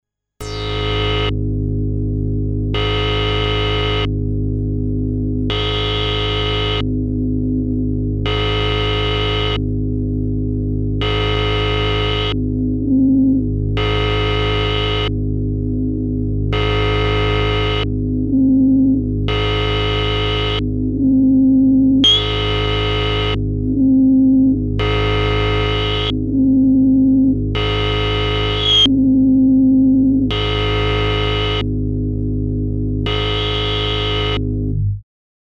А я вот Аэлиту вспоминаю, классный и красивый синт, правда, места занимает.. Кому нужно заменить гудок на мобилу, вот вам с аэлиты "гудки" Вложения gudok.mp3 gudok.mp3 1,4 MB · Просмотры: 1.032